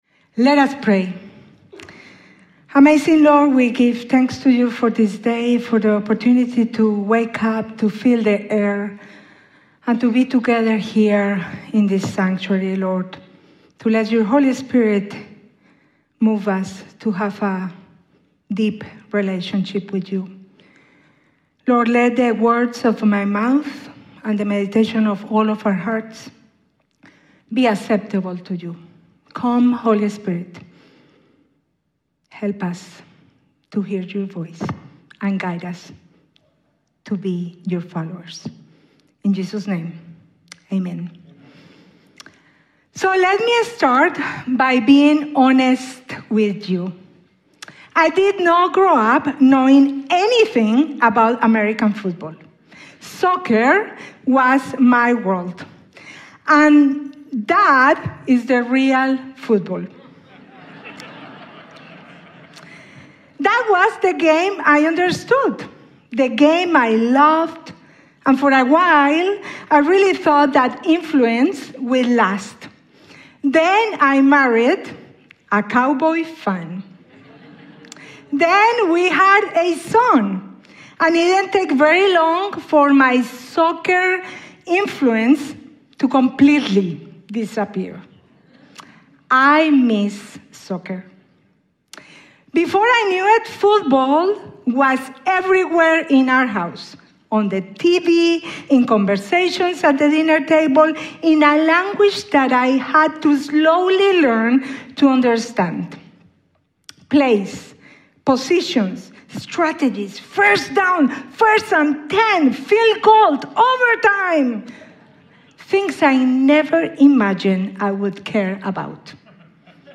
Commitment-sermon - Crossroads United Methodist Church
Sunday Morning Message February 8